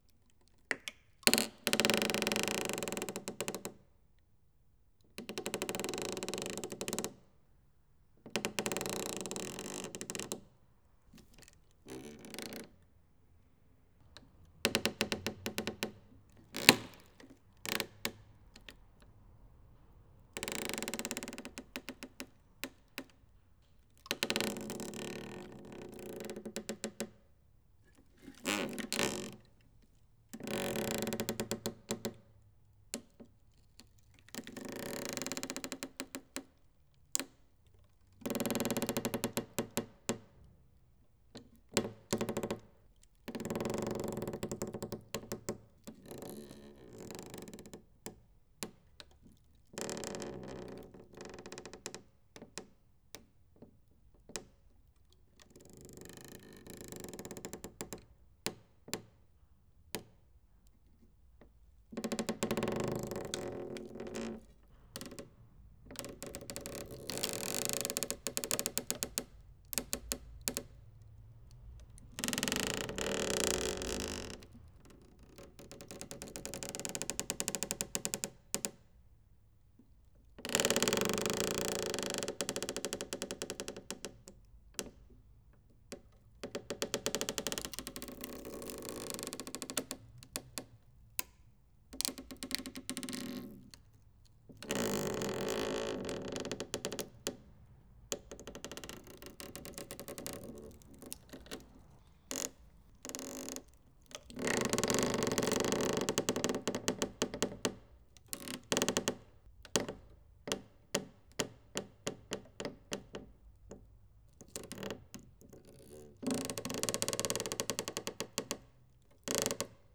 Wood-creak.wav